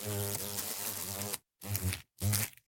insectday_22.ogg